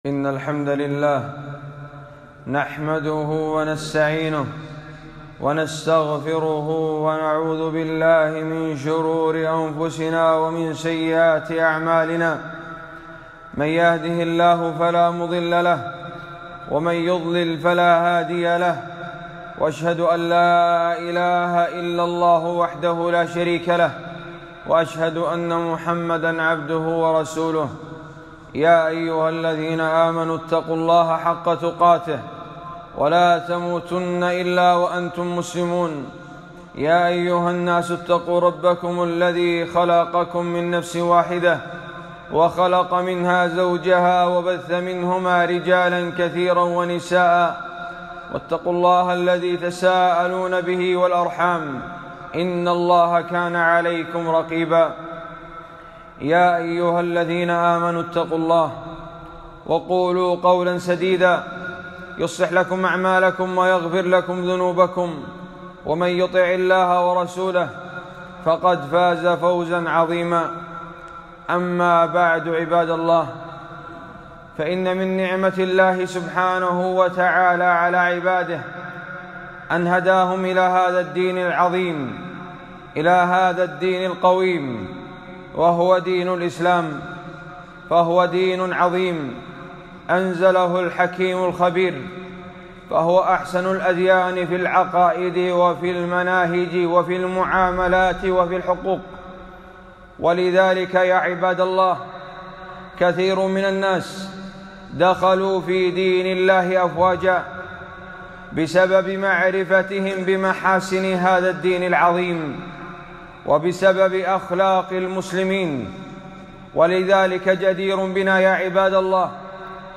خطبة - حقوق كبار السن في الإسلام - دروس الكويت